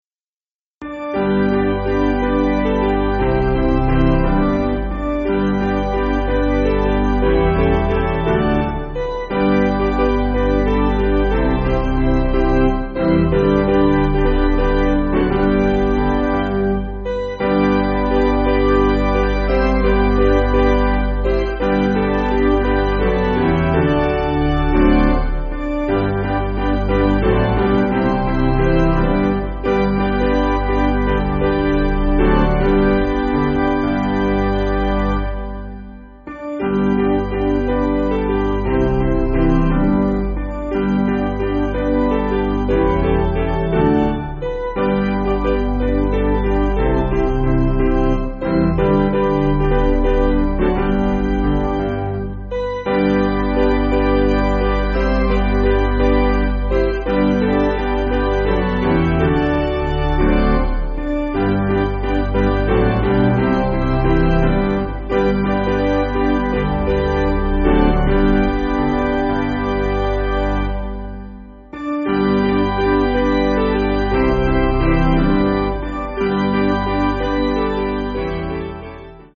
Basic Piano & Organ
(CM)   3/G